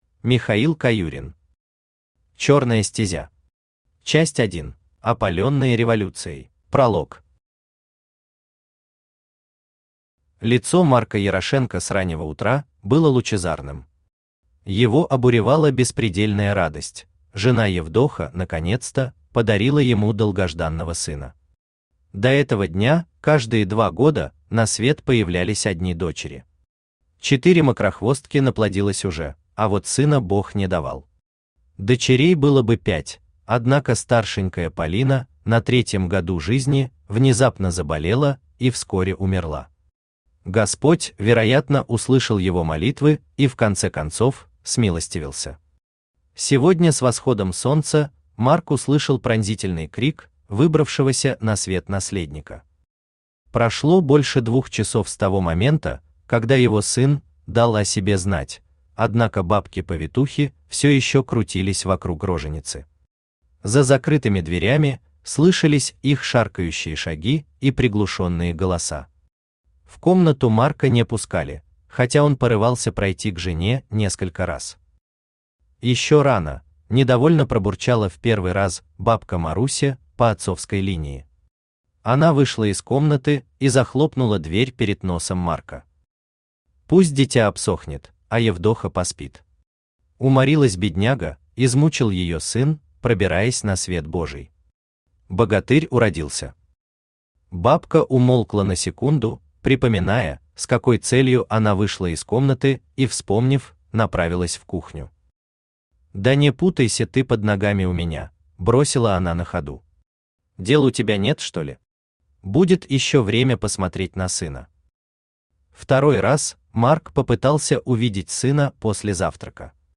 Аудиокнига Чёрная стезя. Часть 1 | Библиотека аудиокниг
Часть 1 Автор Михаил Александрович Каюрин Читает аудиокнигу Авточтец ЛитРес.